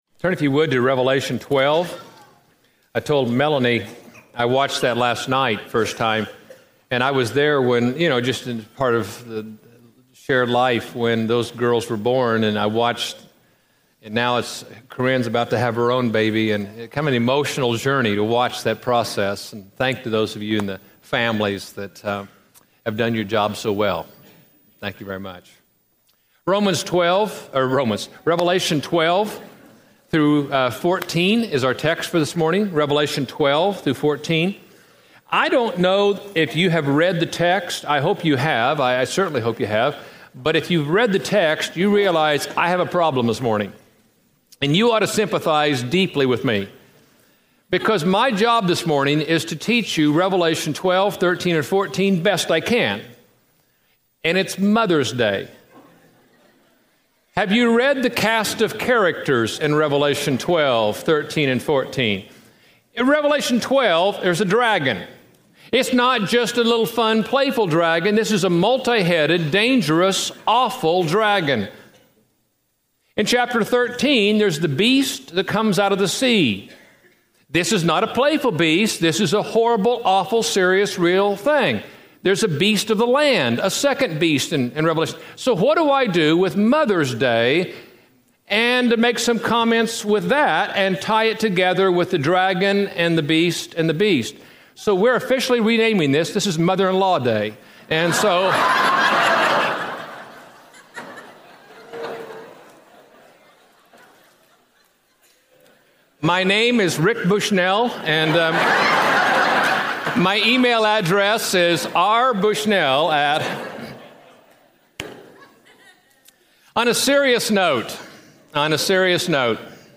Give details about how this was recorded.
Meet Beauty and the Beast Preached at College Heights Christian Church May 13, 2007 Series: Living at Peace in a World Falling to Pieces Scripture: Revelation 12-14 Audio